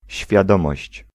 Ääntäminen
Synonyymit connaissance esprit Ääntäminen France: IPA: /kɔ̃.sjɑ̃s/ Haettu sana löytyi näillä lähdekielillä: ranska Käännös Ääninäyte 1. świadomość {f} 2. sumienie {n} 3. przytomność {f} Suku: f .